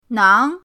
nang2.mp3